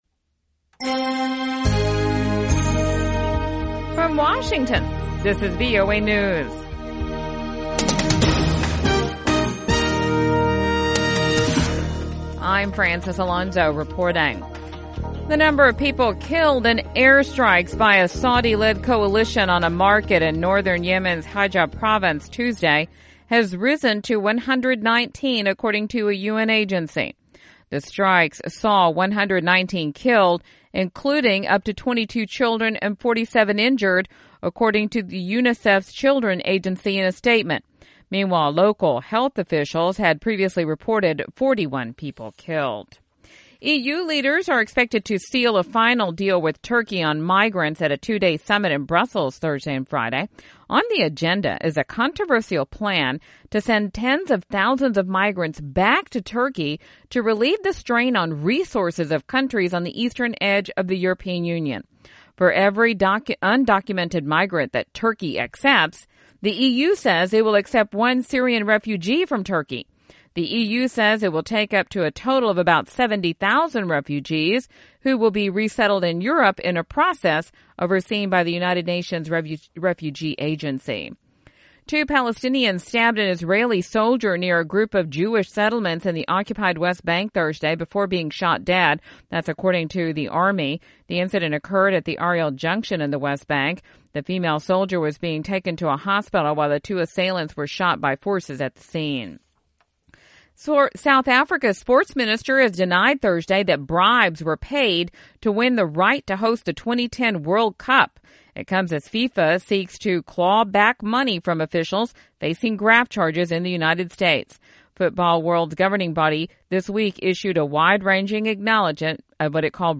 VOA English Newscast: 1400 UTC March 17, 2016